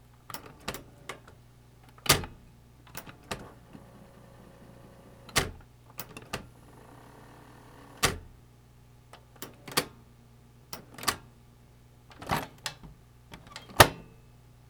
動作音
【メカニズム動作音】再生→停止→早送り→停止→巻戻し→停止→ヘッド回転×２→イジェクト